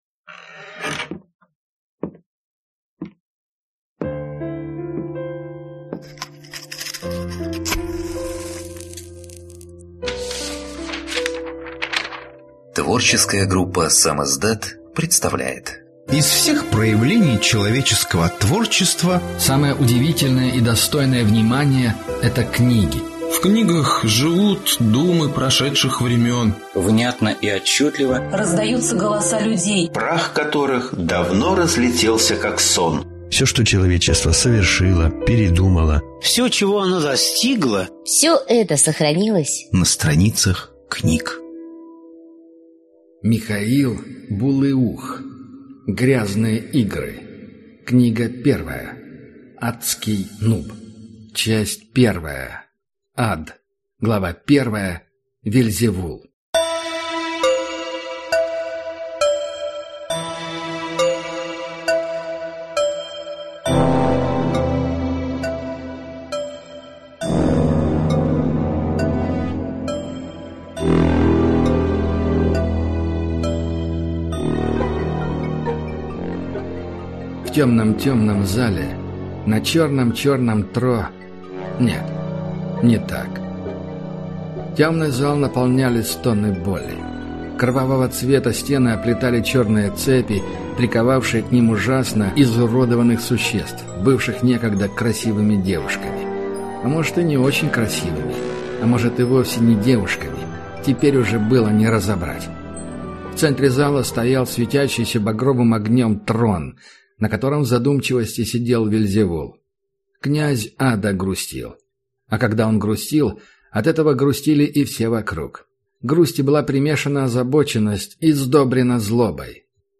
Аудиокнига Аццкий Нуб | Библиотека аудиокниг